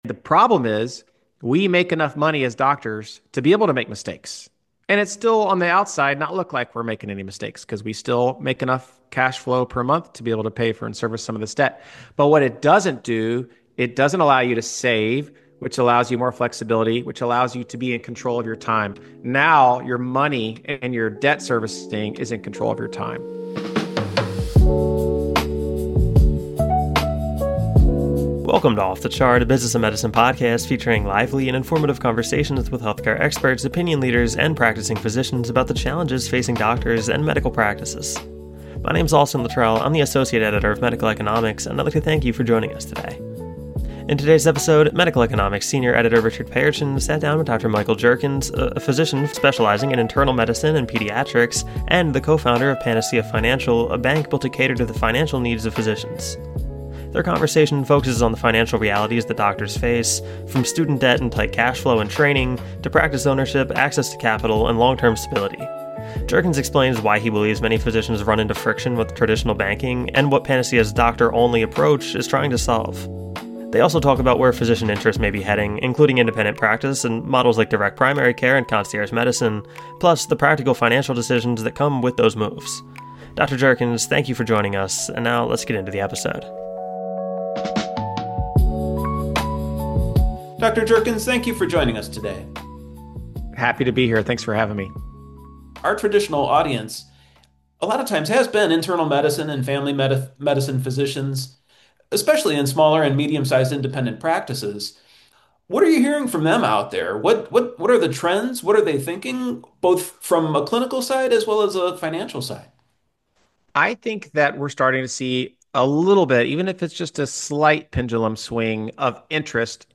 Interview begins